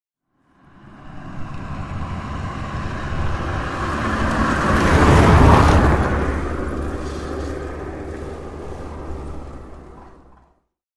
Шум автомобиля на дороге